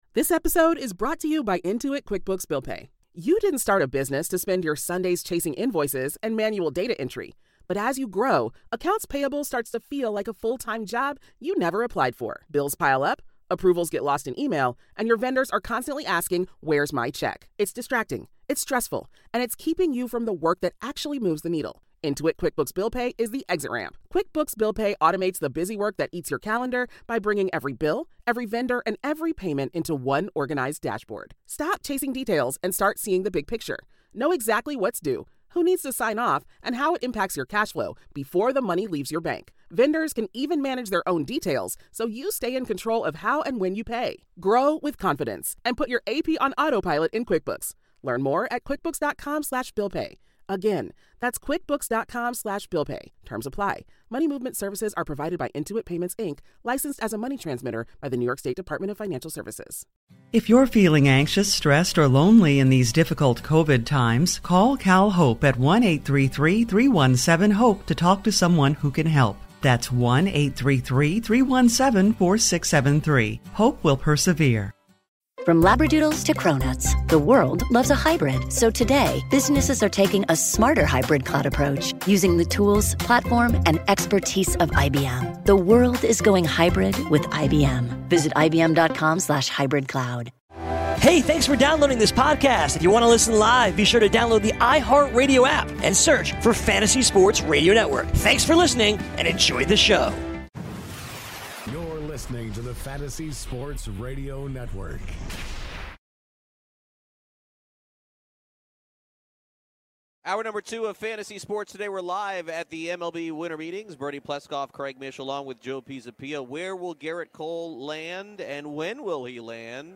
LIVE FROM THE MLB WINTER MEETINGS Day 2